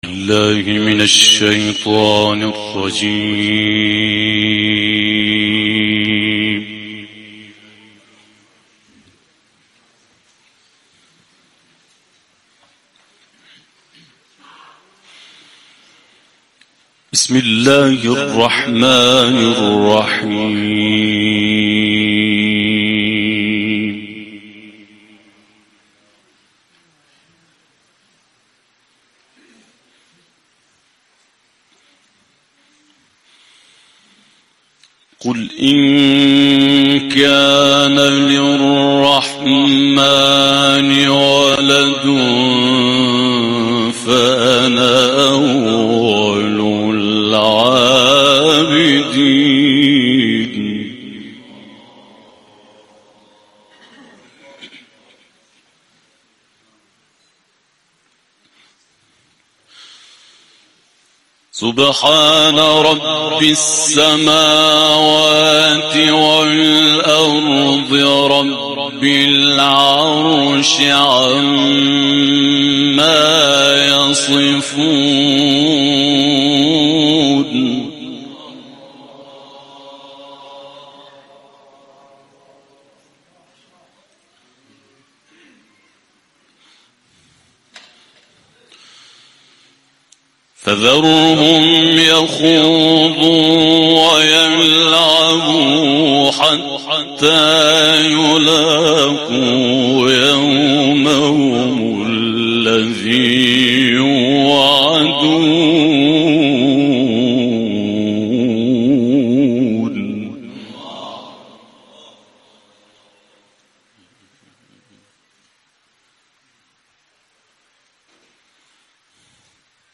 تلاوت
در محفل دهکده قرآنی چارک